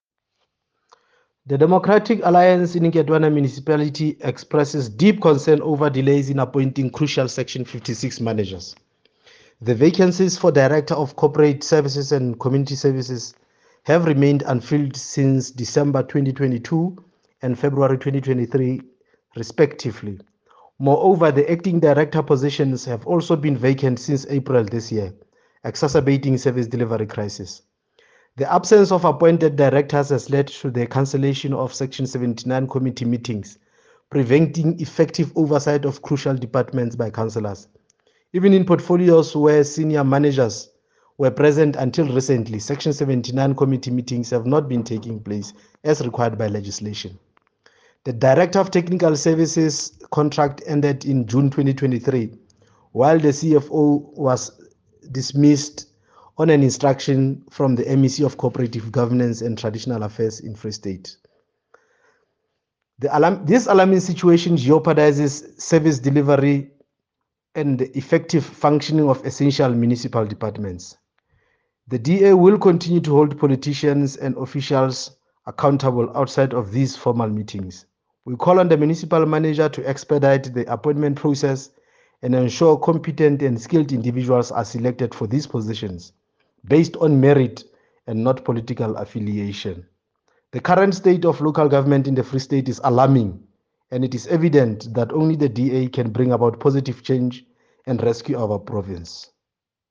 Sesotho soundbites by Cllr Diphapang Mofokeng.